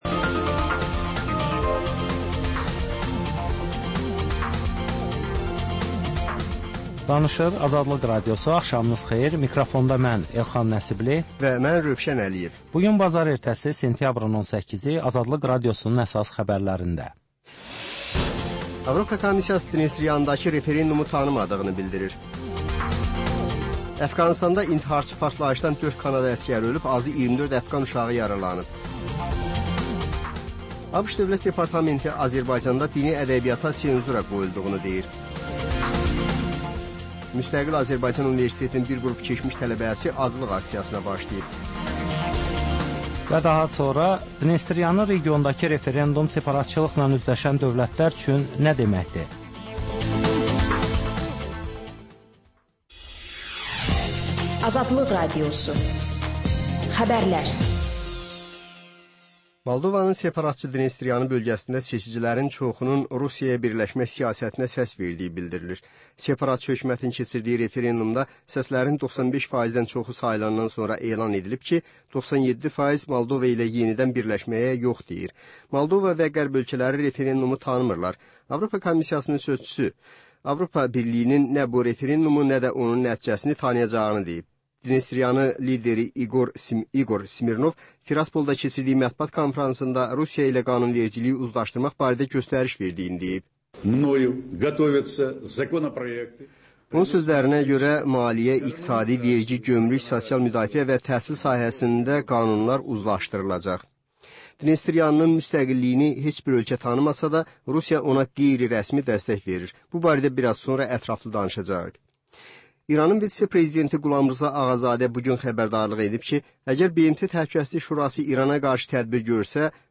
Xəbərlər, reportajlar, müsahibələr. Hadisələrin müzakirəsi, təhlillər, xüsusi reportajlar.